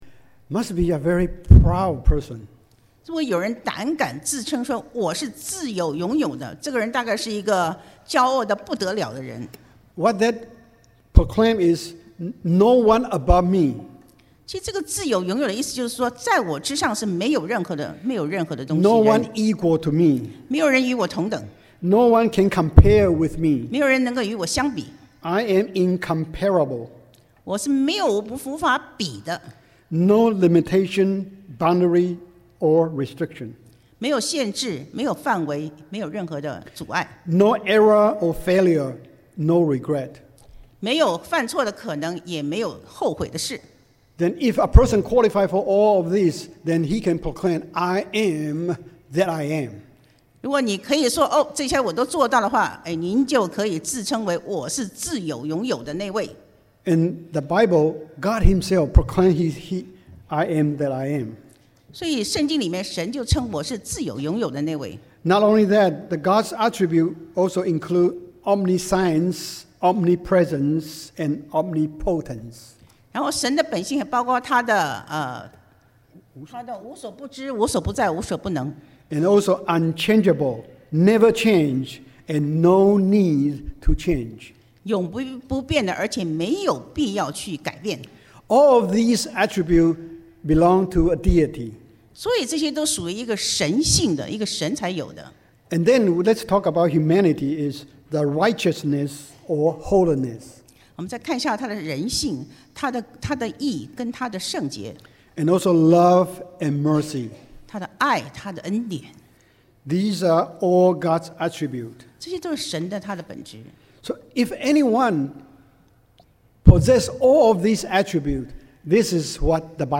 Sermon - CCBCSOC